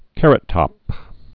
(kărət-tŏp)